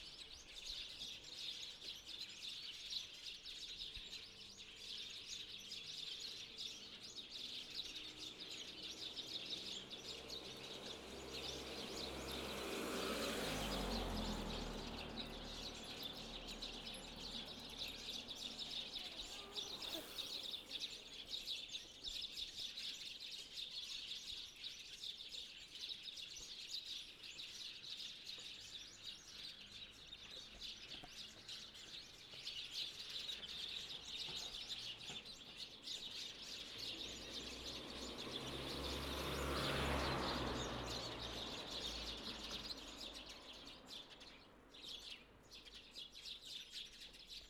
まずは鳥の鳴き声というか、街の雑踏という感じの音から。
フロント側が生垣のスズメたちに向いていて、リアは自分および坂道の上側を向いている。
また前述の通り、H2essentialはMSマイクをデコードしてステレオサウンドを作り出しているわけだが、通りかかるクルマや自転車、人の音からもかなりリアルな立体感が感じられると思う。
H2essential_bird_rear.wav